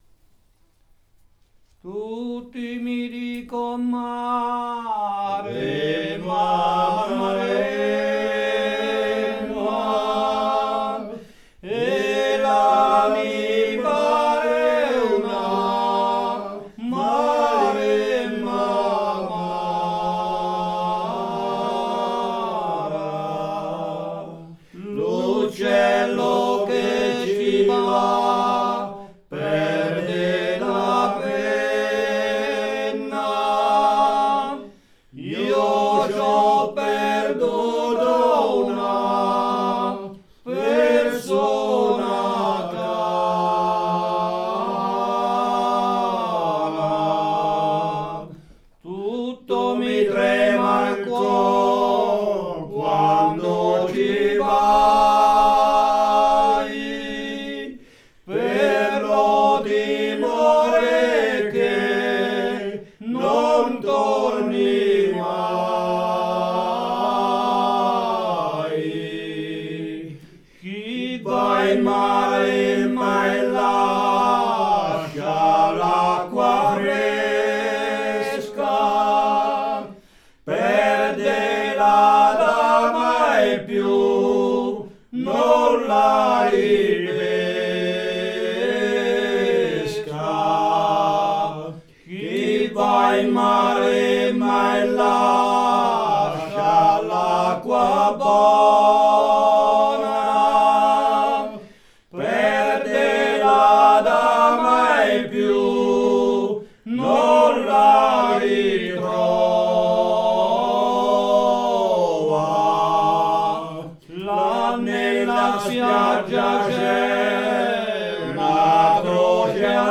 Il paesaggio sonoro del territorio di Latera
Latera canta